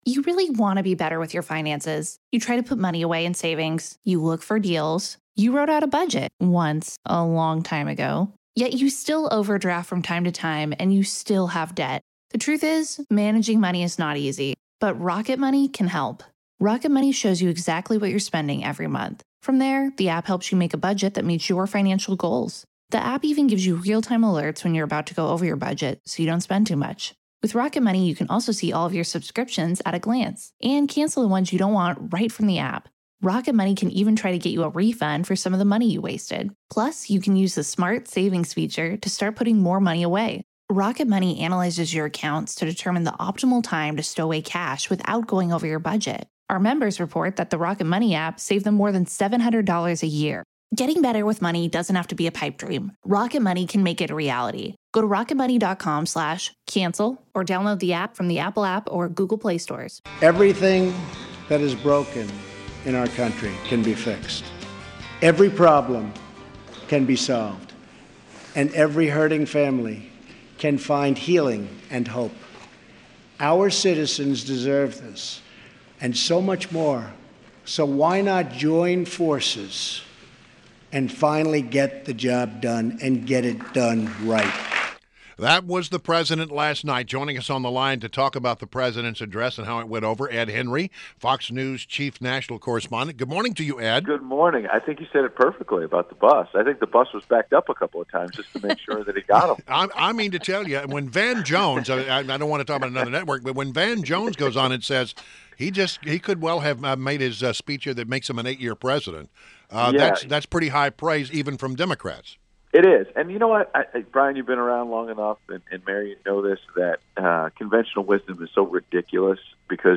WMAL Interview - ED HENRY - 03.01.17
INTERVIEW — ED HENRY – Fox News Chief National Correspondent; Author of upcoming book “42 Faith” (to be released in April)